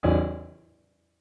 MIDI-Synthesizer/Project/Piano/1.ogg at 51c16a17ac42a0203ee77c8c68e83996ce3f6132